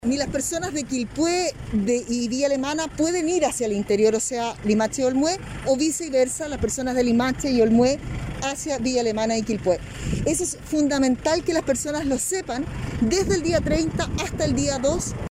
Así lo dio a conocer la gobernadora de la Provincia de Marga Marga, María Carolina Corti, quien además dio el inicio al funcionamiento del control sanitario de la ruta Lo Orozco.